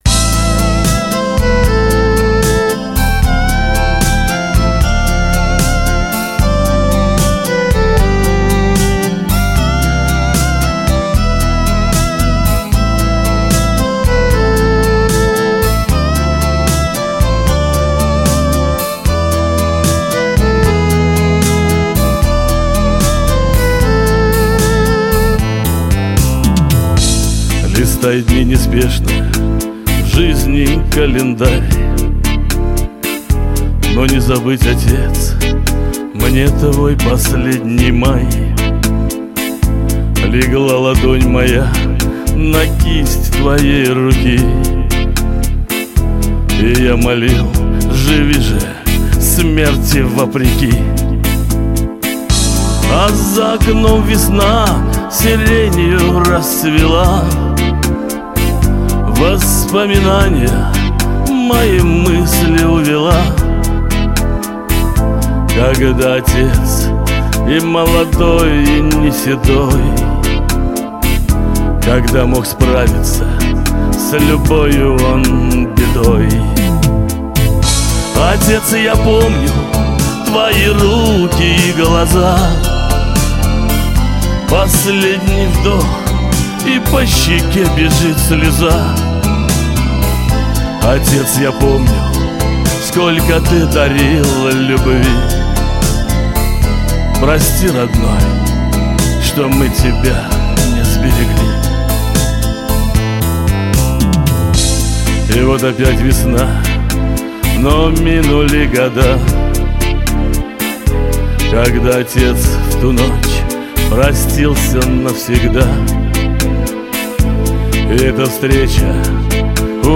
Небольшая подборка прекрасного шансонье...
Какой приятный голос!